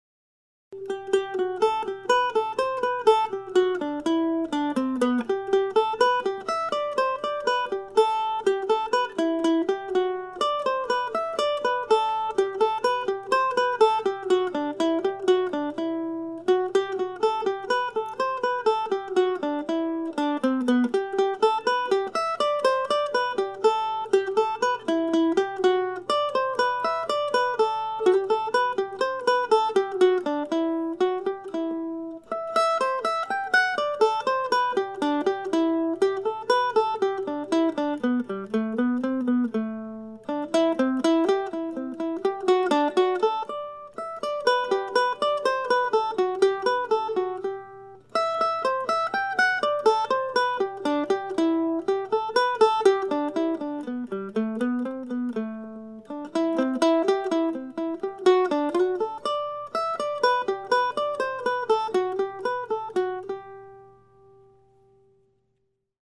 As stated in my previous post, these are short pieces modeled after James Oswald's 18th century divertimentos for "guittar" and titled after some of my favorite places here in the Decorah area.
I'll be playing all ten of these Postcards tomorrow night at Java John's Coffee House, along with music by James Oswald and others, from 7:00-9:00 p.m. Drop by if you would like to hear some solo mandolin music.